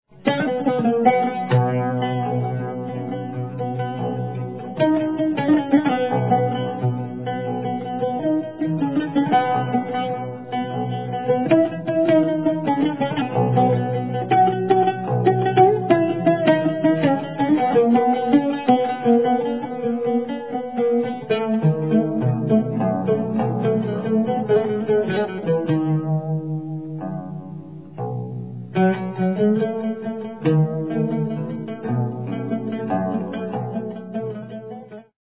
Oud Solo